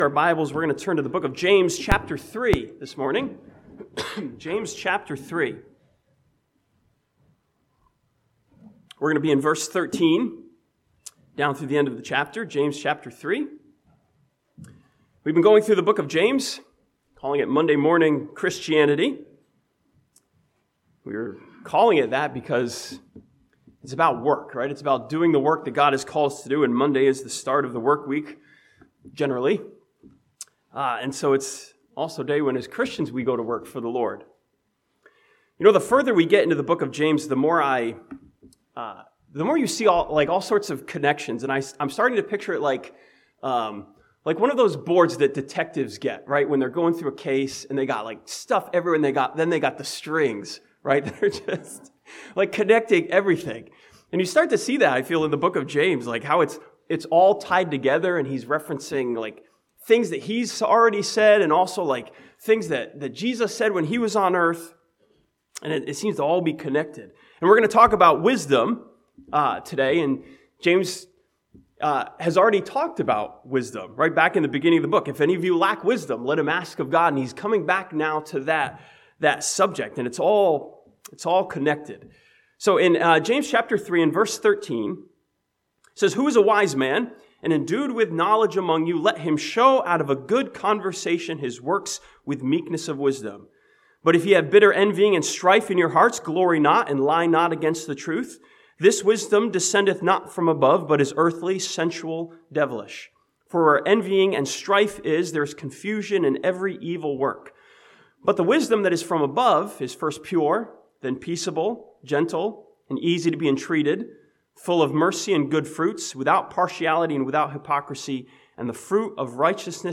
This sermon from James chapter 3 challenges us to do the work of wisdom - doing things God's way.